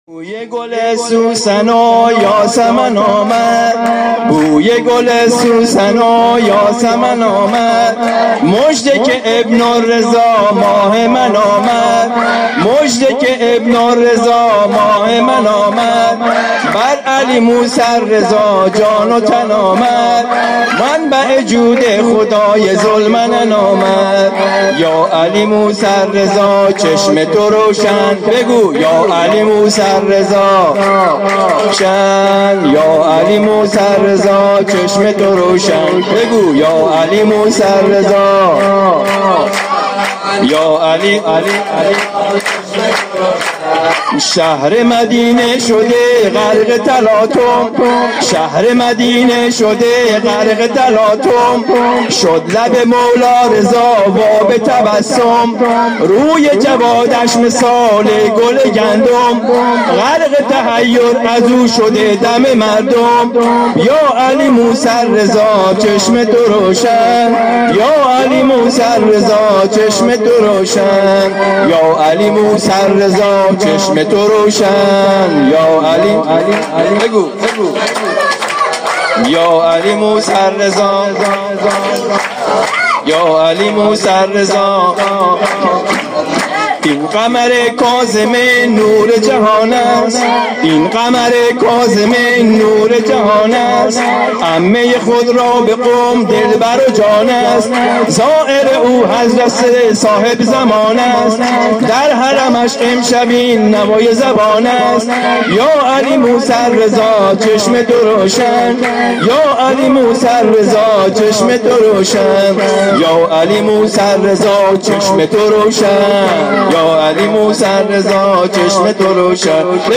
ولادت امام محمد تقی علیه السلام سه شنبه ۱۸دی ۱۴۰۳
جشن ها